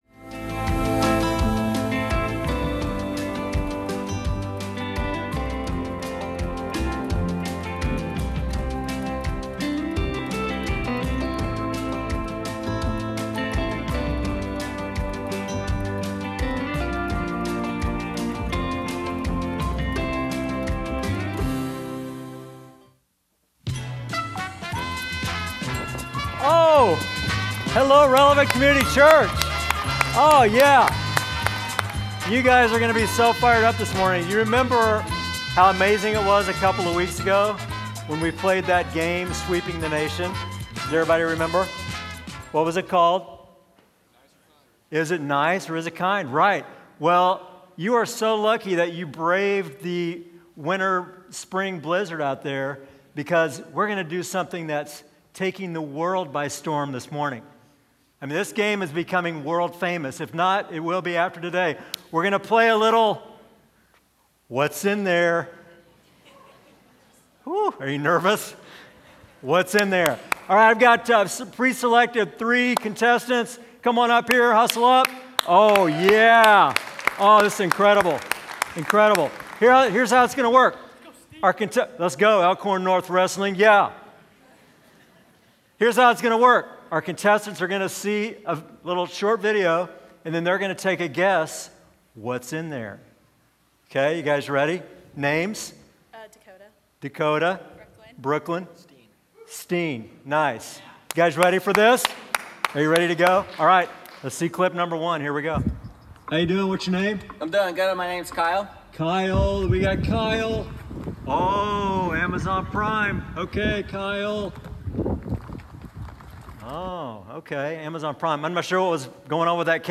Sunday Sermons FruitFULL, Week 7: "Faithfulness" Mar 08 2026 | 00:34:16 1x 00:00 / 00:34:16 Subscribe Share Apple Podcasts Spotify Overcast RSS Feed Share Link Embed